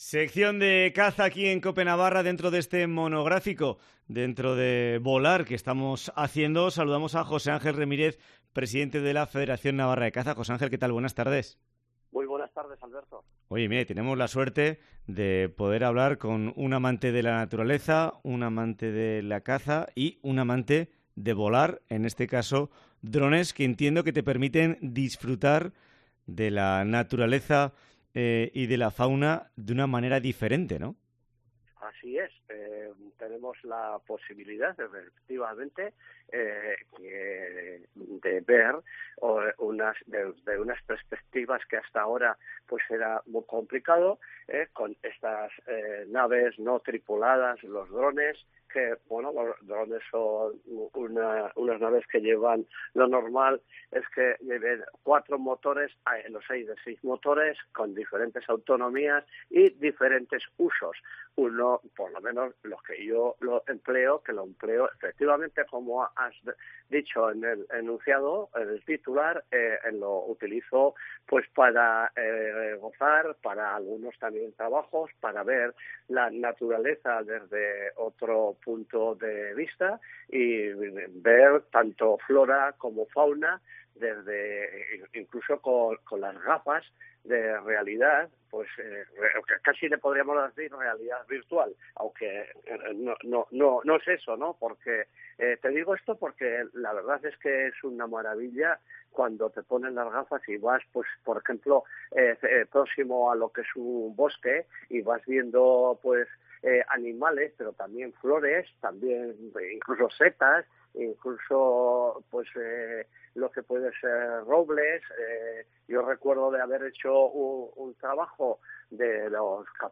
Semana dedicada en COPE Navarra a "volar". Entrevista